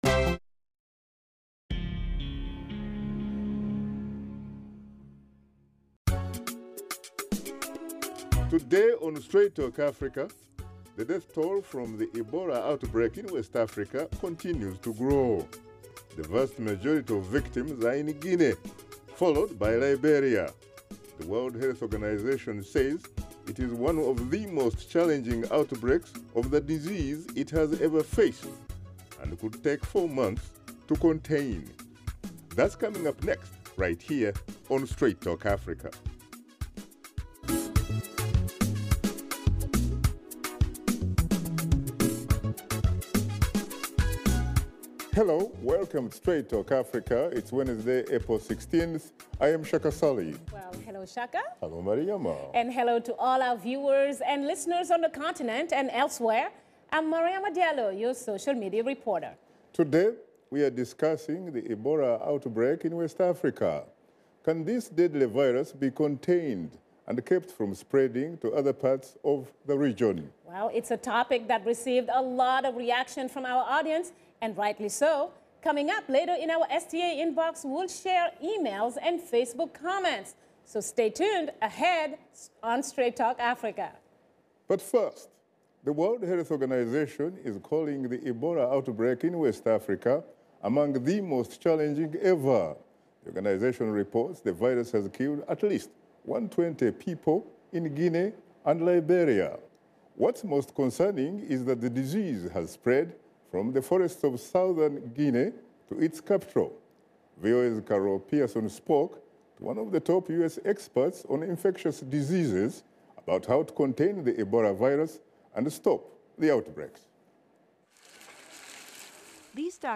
Straight Talk Africa host Shaka Ssali and his guests examine the Ebola outbreak in West Africa and discuss how nations in the region can combat the deadly disease.